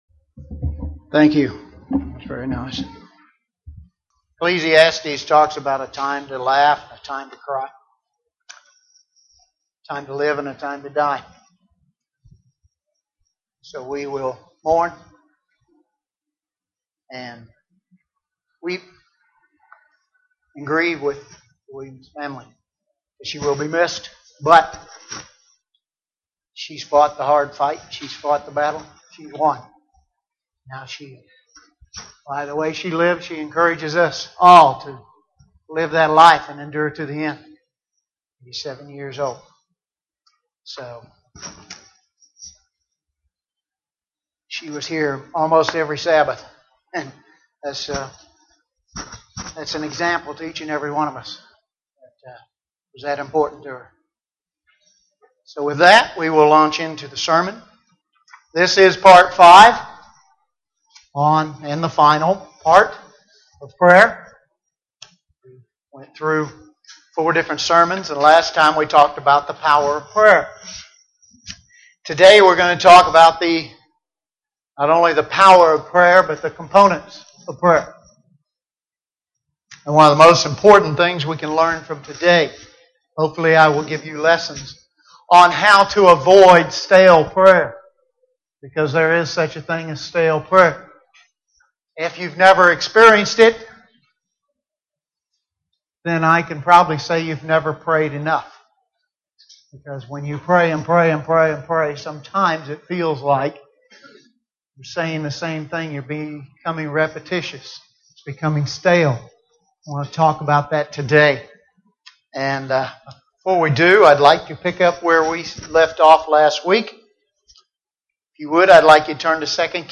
Part 5 in a series on prayer, this sermon looks at examples of great prayers in the Bible.